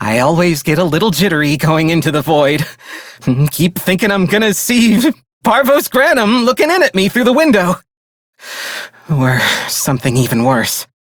DRJCrewIdle0760RJPSMale_en.ogg